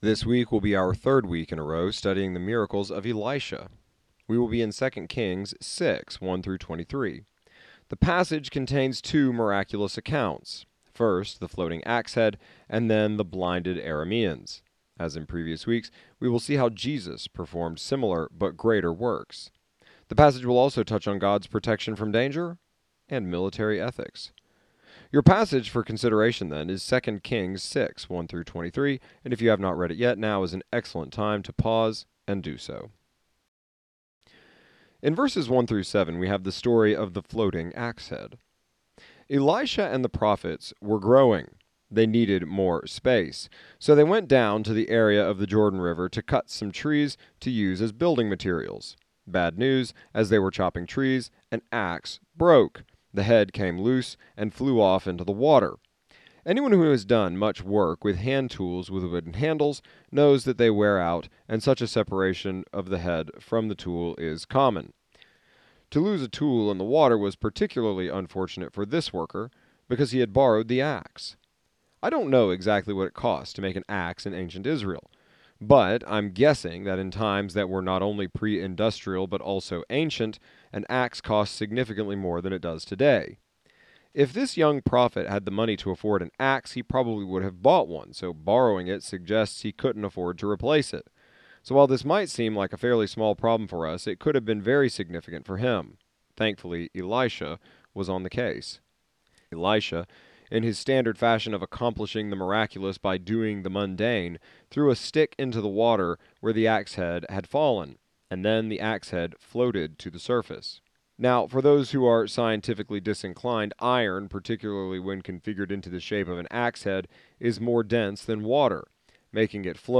Jail Sermon